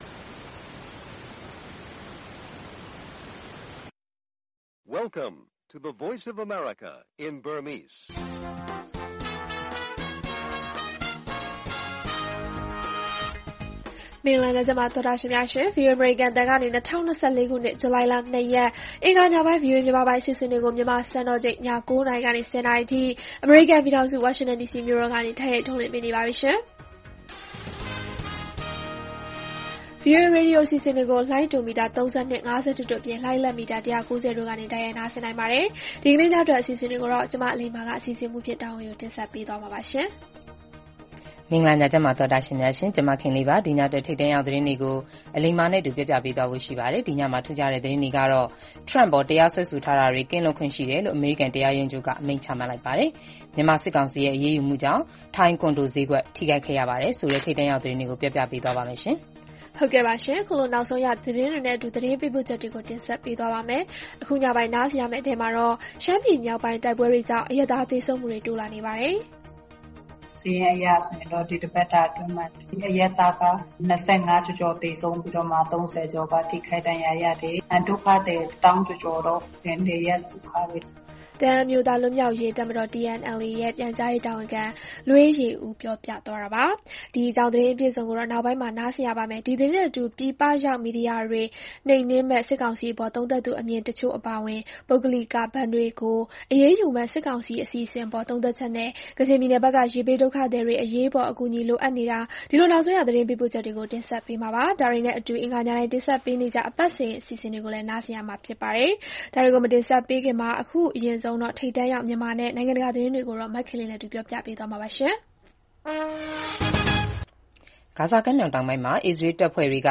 ရှမ်းပြည်မြောက်ပိုင်း တိုက်ပွဲတွေကြား အရပ်သား သေဆုံးမှုတွေတိုး၊ ပြည်ပရောက်မီဒီယာတွေ နှိမ်နင်းမယ့် စစ်ကောင်စီအစီအစဉ်နဲ့ ပတ်သက်လို့ ဆက်သွယ်မေးမြန်းချက် စတာတွေအပြင် မြေကြီးသြဇာ၊ မြန်မာနဲ့ ယူကရိန်း နှိုင်းယှဉ်ချက်၊ အမေရိကန်နိုင်ငံရေး၊ ကမ္ဘာတလွှား ခရီးသွား အစီအစဉ်တွေကို တင်ဆက်ထားပါတယ်။